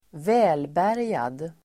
Uttal: [²v'ä:lbär:jad]